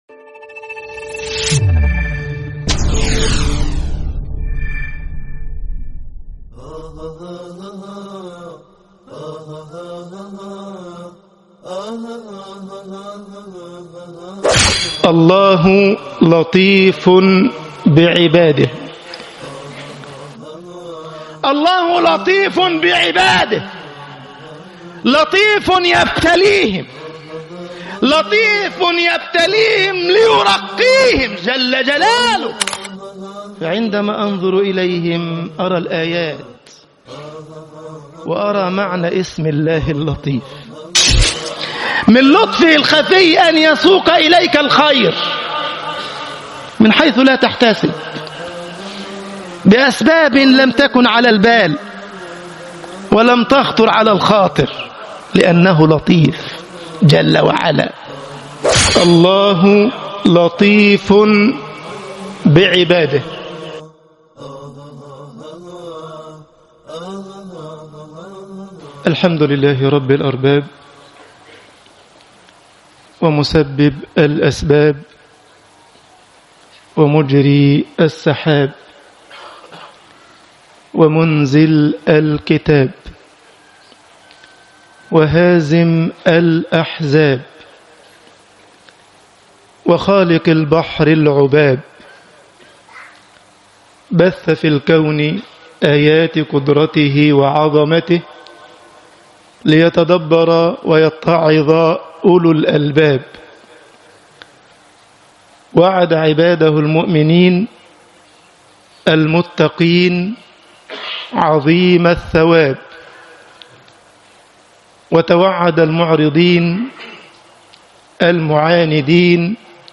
خطب الجمعة - مصر الله لطيف بعباده طباعة البريد الإلكتروني التفاصيل كتب بواسطة